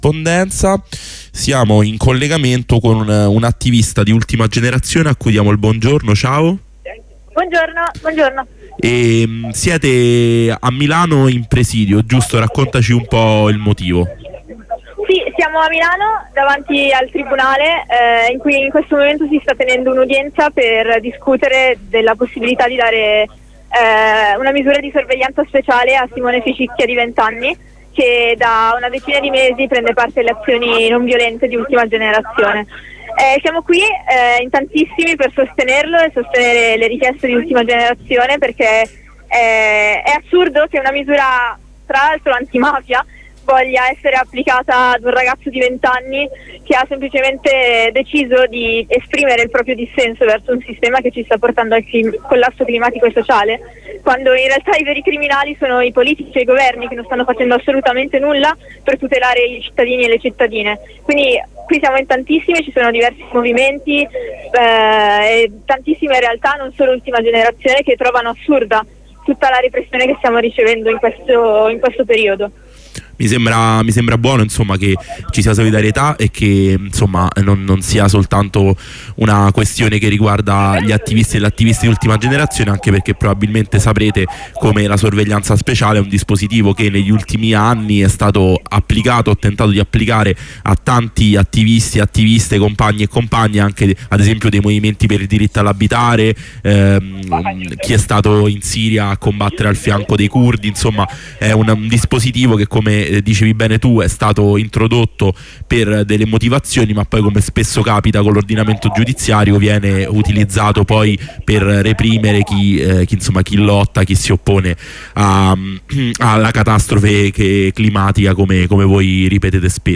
Attivista Ultima Generazione